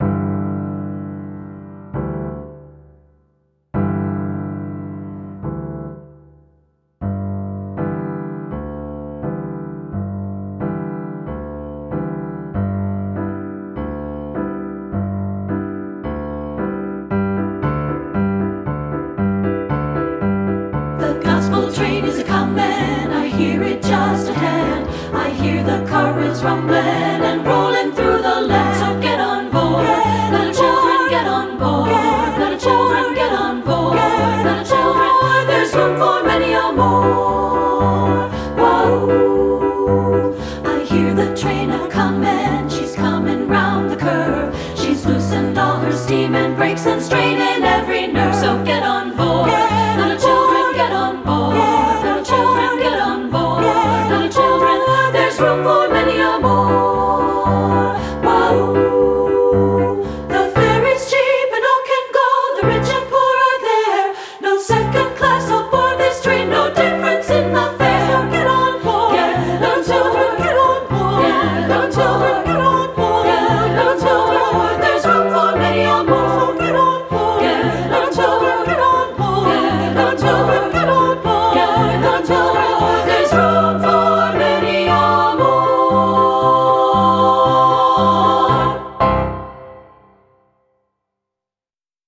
Traditional Spiritual
• Soprano 1
• Soprano 2
• Alto
• Piano
Studio Recording
Ensemble: Treble Chorus
Key: G major, C major
Tempo: q = 116
Accompanied: Accompanied Chorus